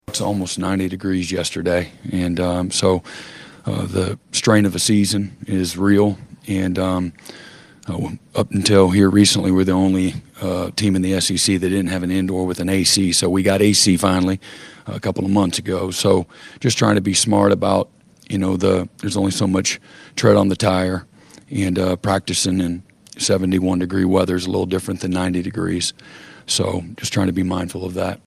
Head coach Brent Venables says that has nothing to do with trying to hide Mateers’ thumb from the media.